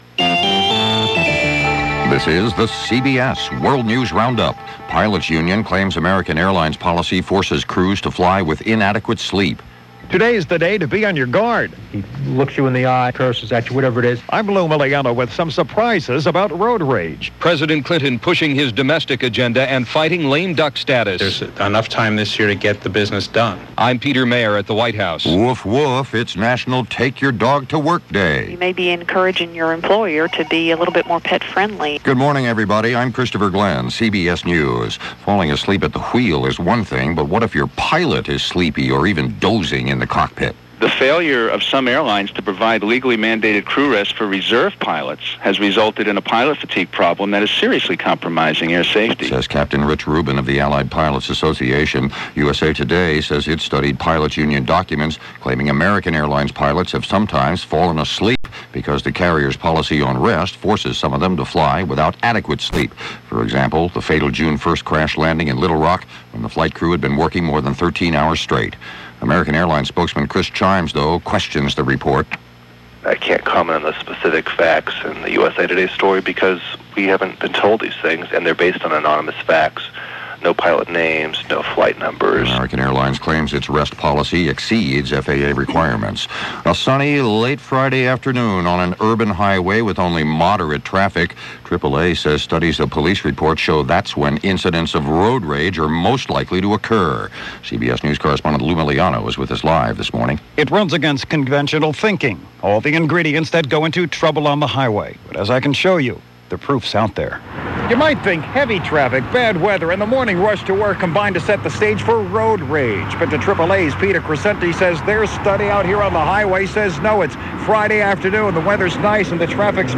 And that’s just a taste of what went on, this June 25, 1999 as reported by The CBS World News Roundup.